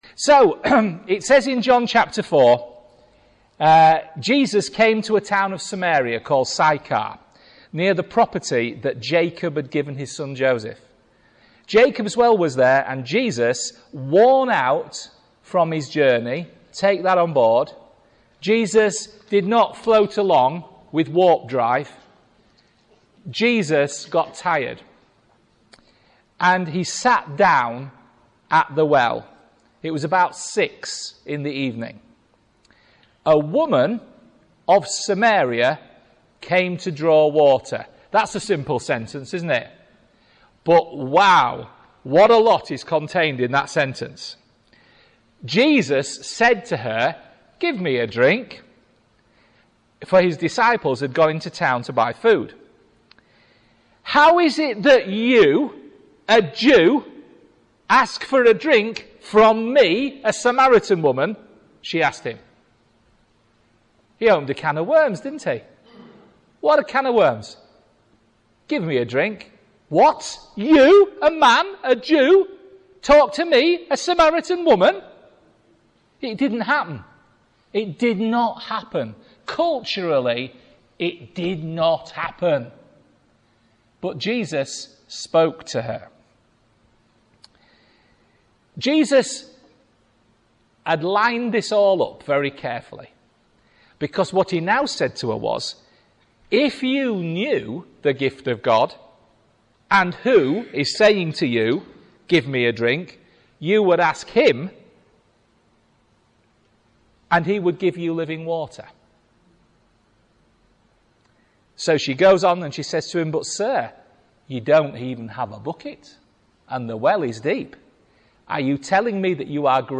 A short message from the fourth chapter of John's gospel about the Samaritan woman at the well in Sychar